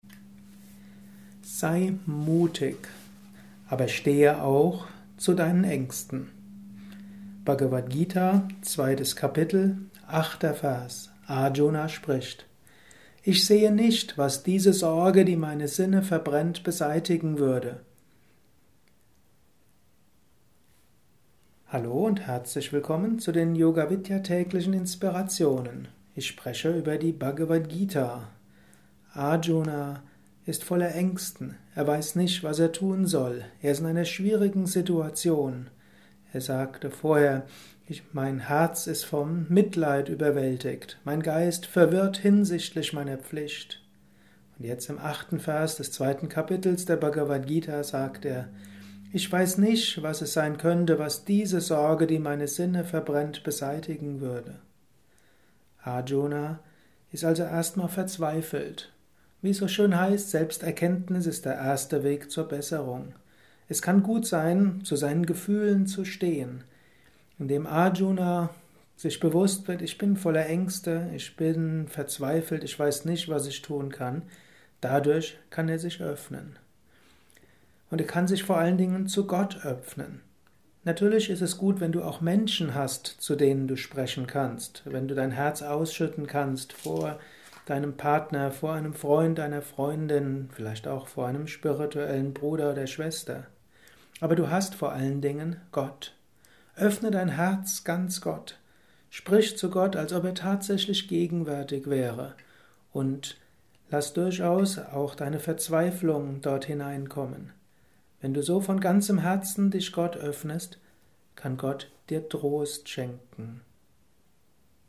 Dies ist ein kurzer Vortrag als Inspiration